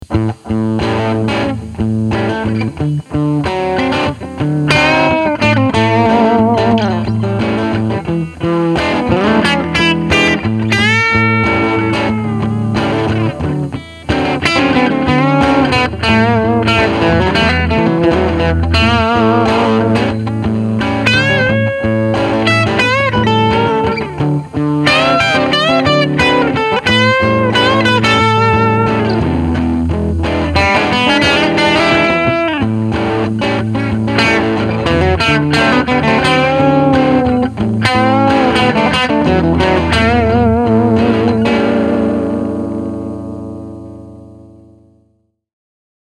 Middle position, with some grind for rhythm; bridge pickup for lead.
Notice that it’s bright and almost twangy.
For the clean clips, I used a ’65 Twin Reverb model, and for the crunchy clip, I used a ’59 Bassman.
335_mid_bridge_crunchy.mp3